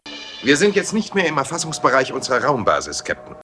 Falls jemand einen aussagekräftigen Reichweitentest durchführen will, dann braucht er ein sehr großes Modell und die richtige" wav.datei" für schwaches Signal, um ein Gefühl für die Entfernungen zu bekommen.